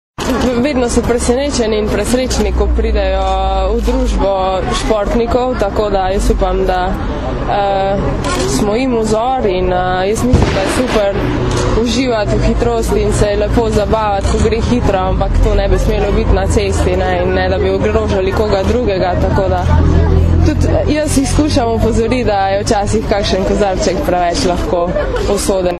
Ministrica za notranje zadeve Katarina Kresal in namestnica generalnega direktorja policije mag. Tatjana Bobnar sta danes, 5. aprila, skupaj z učenci OŠ Oskarja Kovačiča, vrhunskima športnikoma Tino Maze in Dejanom Zavcem ter ljubljanskimi policisti opozorili na varnost otrok v prometu.
Zvočni posnetek izjave Tine Maze (mp3)